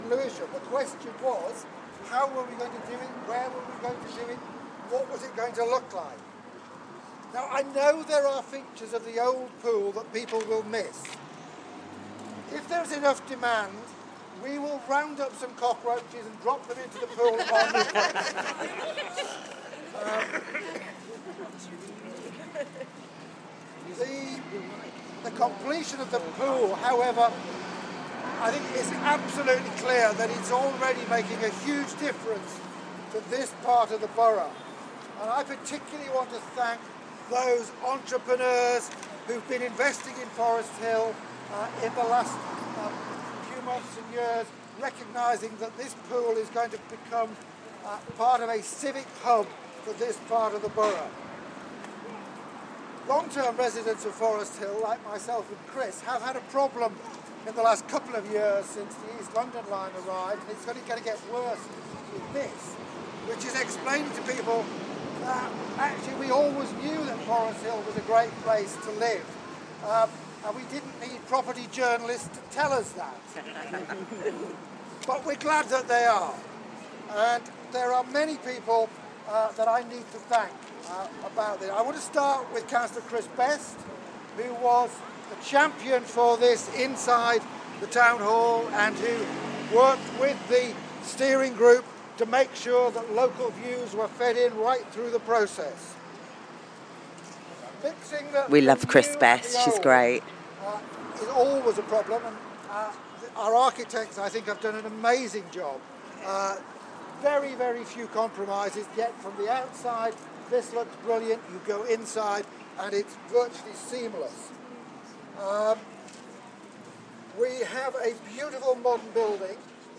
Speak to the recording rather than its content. Opening speech at Forest Hill Pool